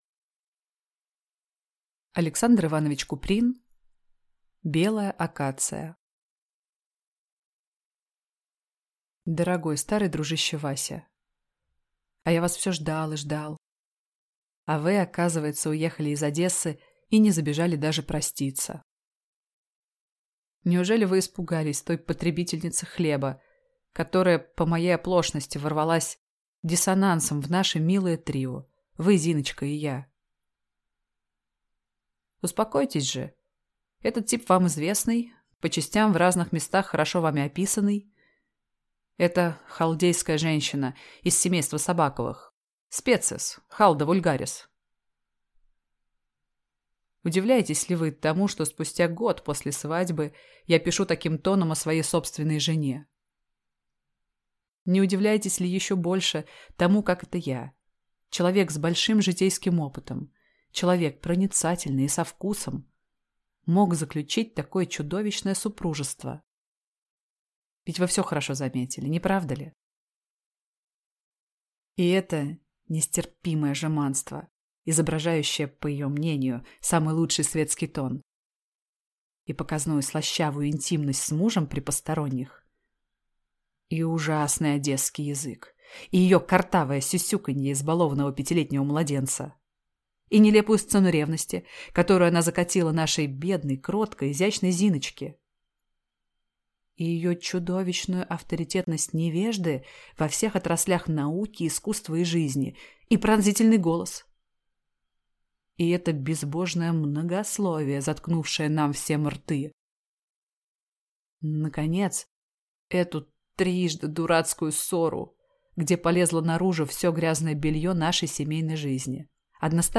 Аудиокнига Белая акация | Библиотека аудиокниг